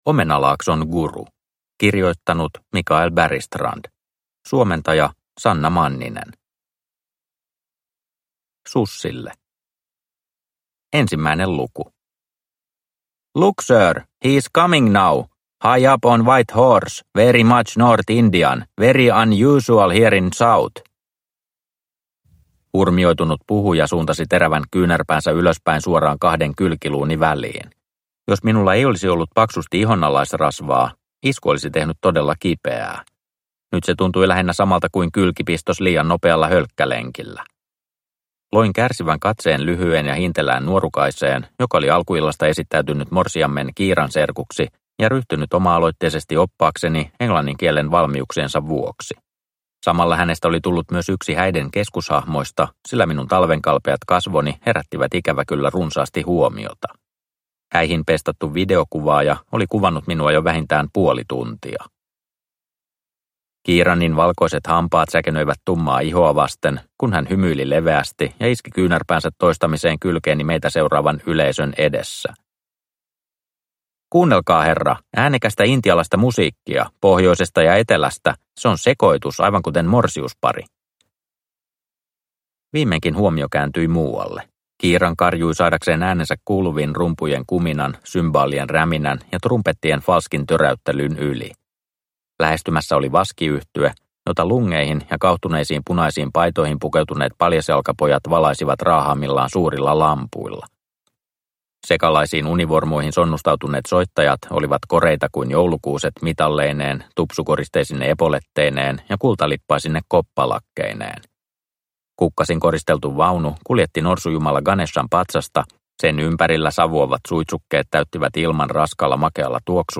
Omenalaakson guru – Ljudbok – Laddas ner